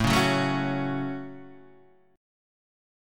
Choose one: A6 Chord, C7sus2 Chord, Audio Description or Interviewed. A6 Chord